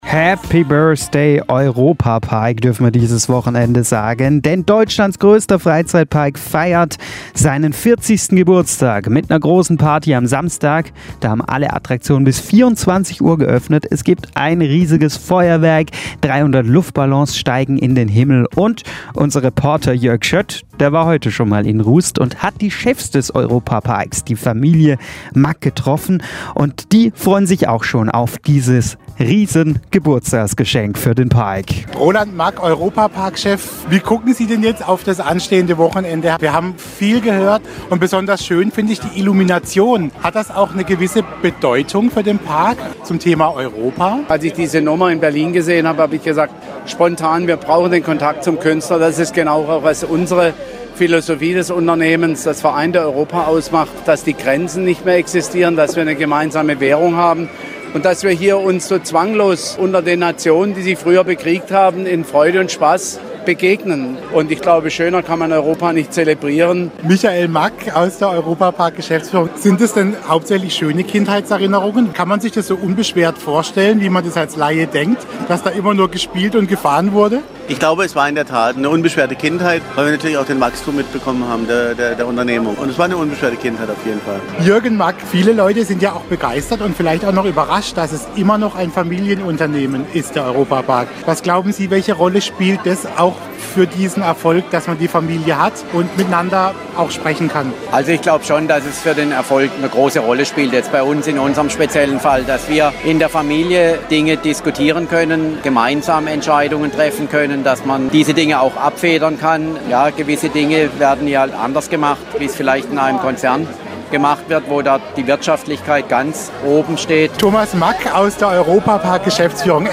Was der Ehrengast Siegfried Rauch und die Geschäftsführung über den Europa-Park im Jahre 2015 zu sagen haben, könnt ihr in folgenden Radiomitschnitten nachhören, die uns freundlicherweise Hitradio Ohr/Schwarzwaldradio zur Verfügung gestellt hat:
Interview Siegfried Rauch